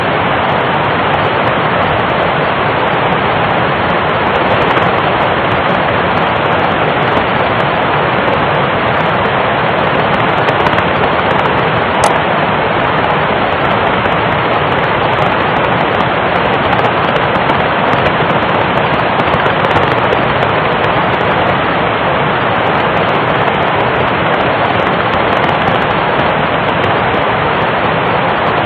March 19, 2004 Io-B S-bursts ( short bursts ) 20.1 MHz 0216 UT CML III 120.9 Io Phase 97.4 Note: The distinct click at 12 seconds into sound file is a computer artifact and not jovian.